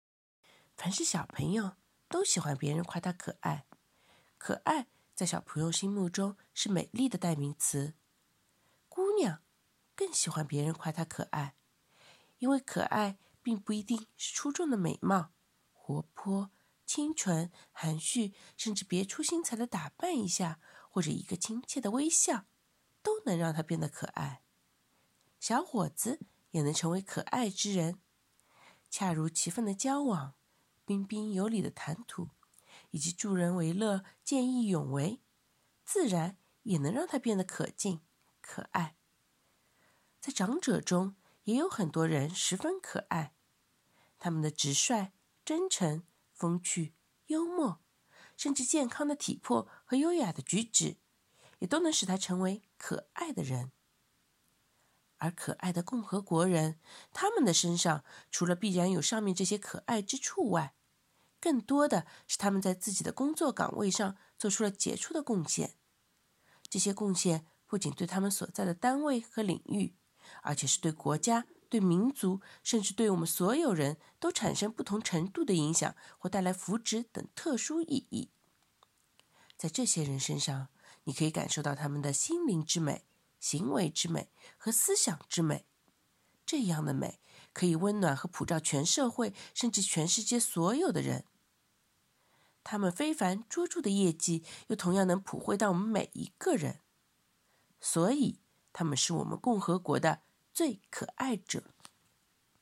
诵读音频：点击收听